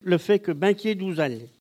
Localisation Sallertaine
Catégorie Locution